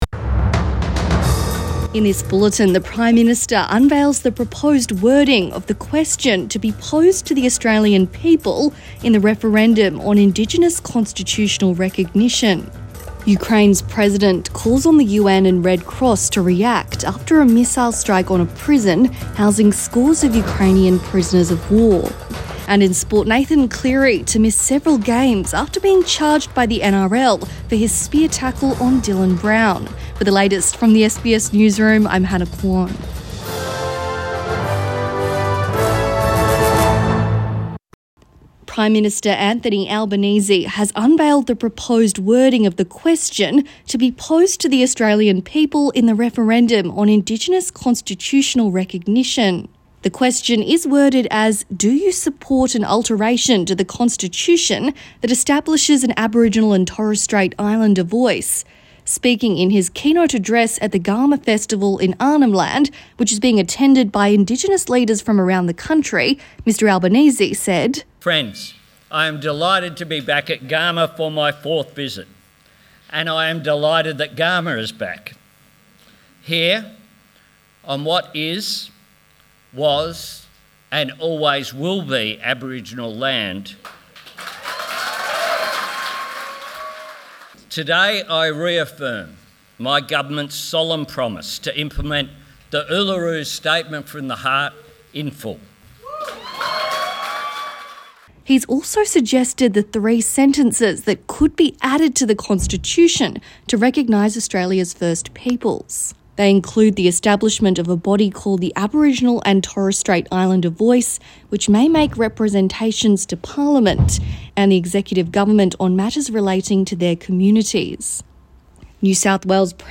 Midday bulletin 30 July 2022